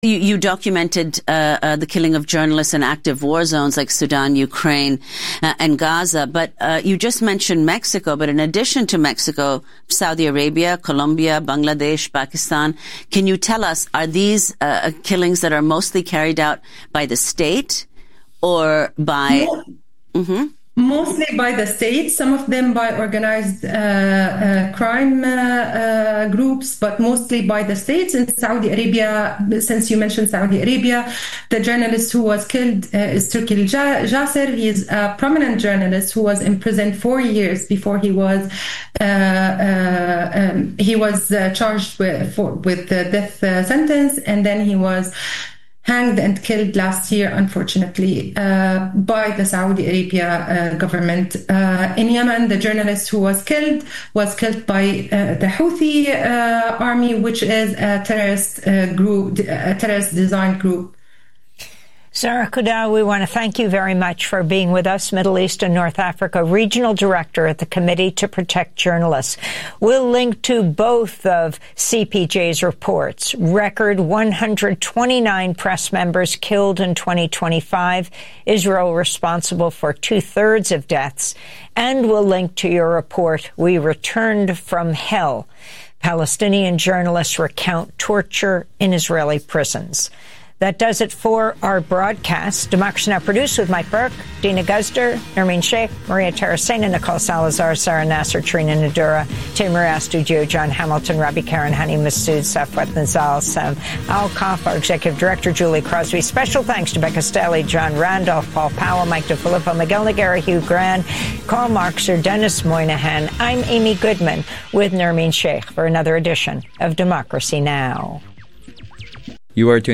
Call-in radio show, every Thursday from 8 AM to 9 AM.